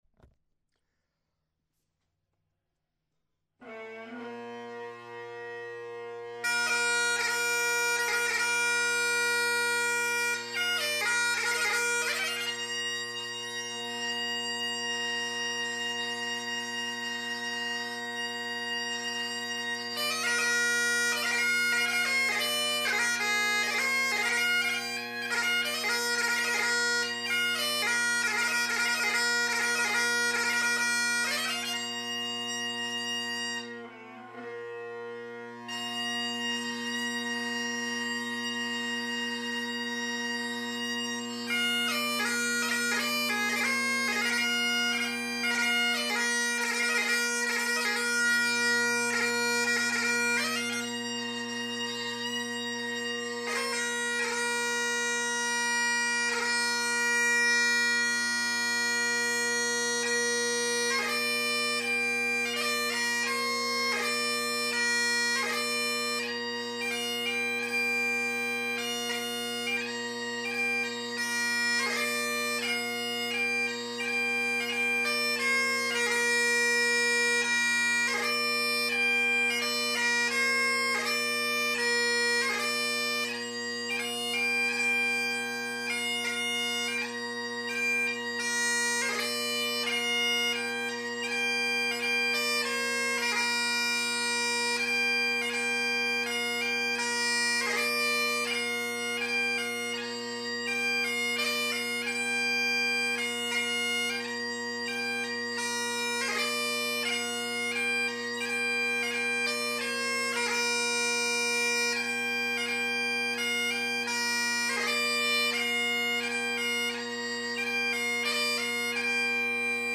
one from the 50’s and the other from the 70’s. Both played with exactly the same setup (Ezee tenors and original Kinnaird bass). Interesting the different sounds emitted from the same maker, just different time periods.
70’s Sinclair_Ezeedrone tennors_Kinnaird bass
70s-Sinclair_Ezeedrone-tennors_Kinnaird-bass.mp3